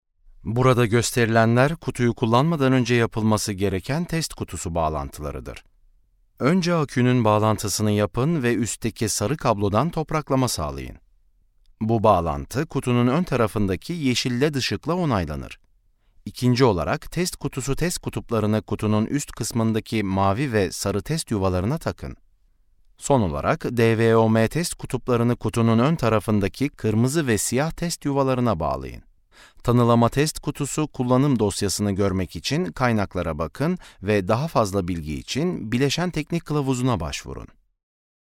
Sprecher türkisch.
Kein Dialekt
Sprechprobe: eLearning (Muttersprache):